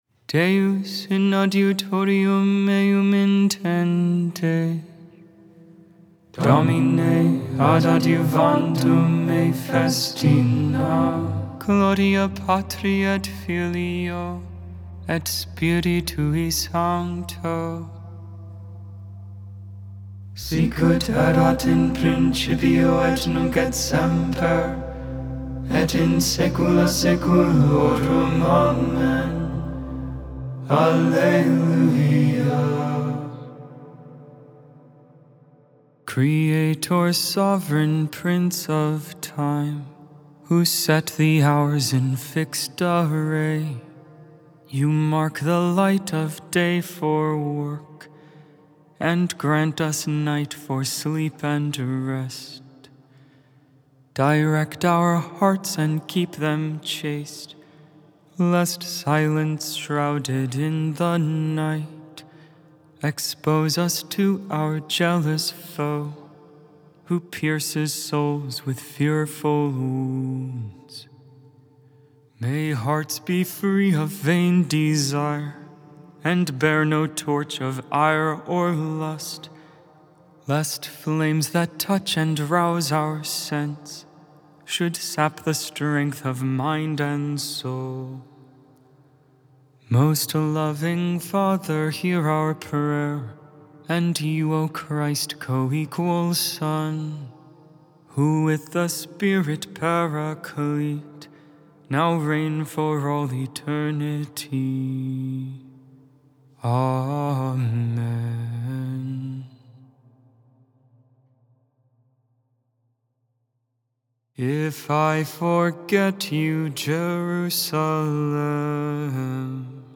11.12.24 Vespers, Tuesday Evening Prayer